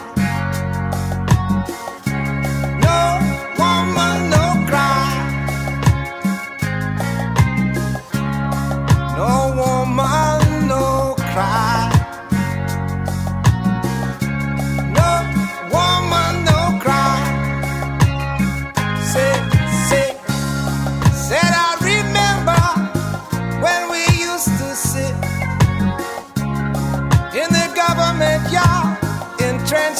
Great Reggae requires Great Bass